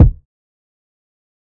TC Kick 05.wav